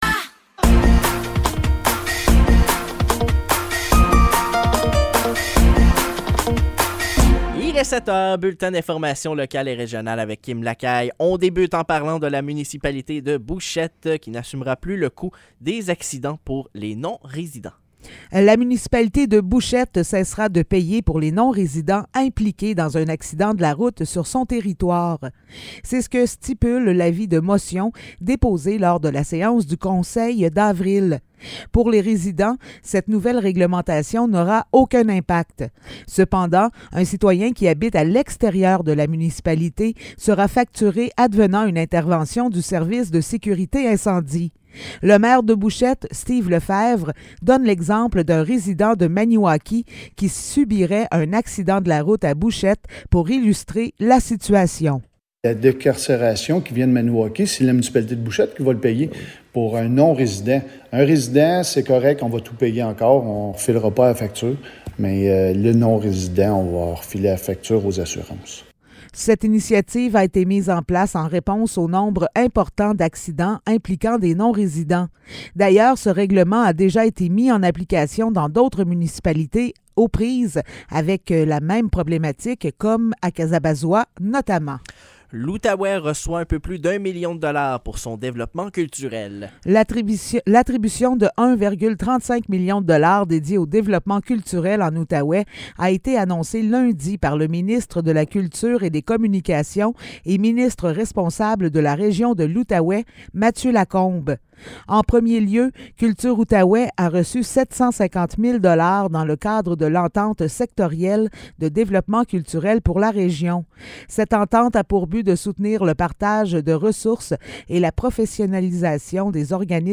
Nouvelles locales - 4 mai 2023 - 7 h